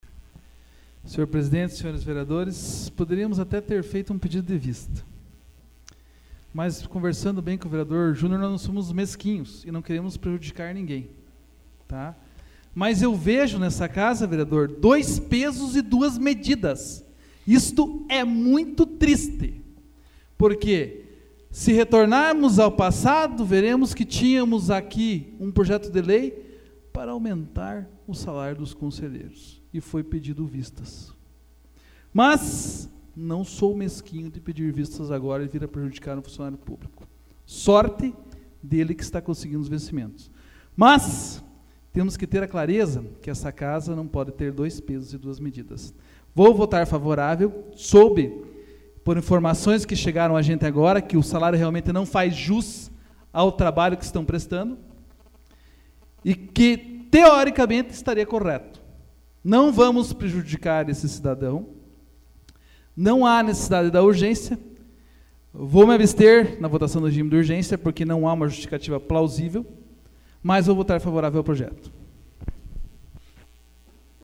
Discussão do Regime de urgênci AVULSO 25/03/2014 João Marcos Cuba